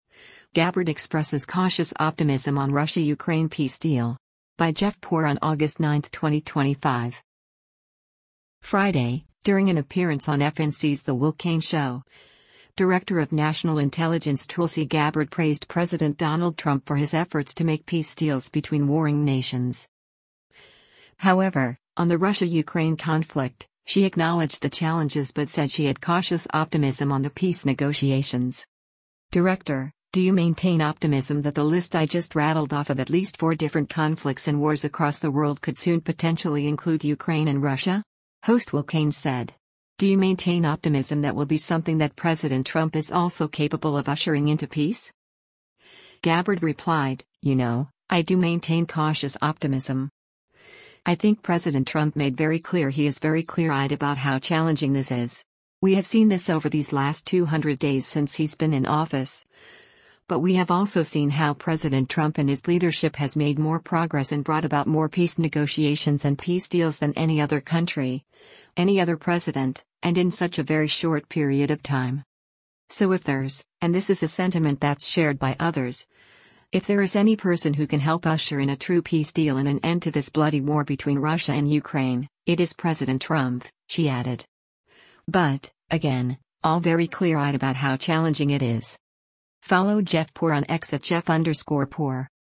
Friday, during an appearance on FNC’s “The Will Cain Show,” Director of National Intelligence Tulsi Gabbard praised President Donald Trump for his efforts to make peace deals between warring nations.